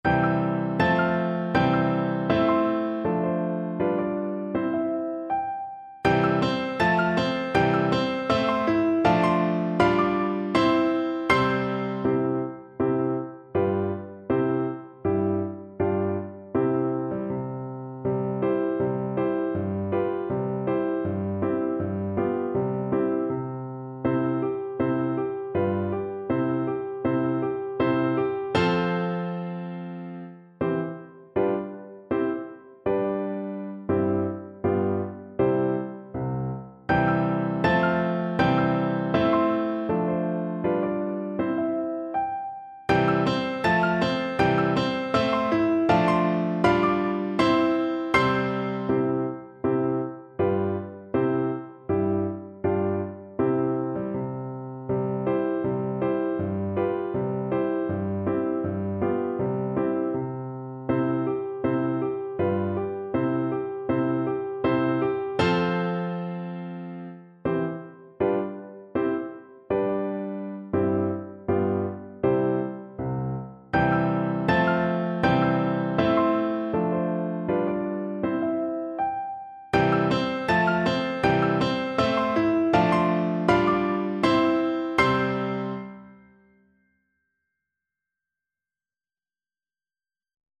2/4 (View more 2/4 Music)
G5-A6
Andante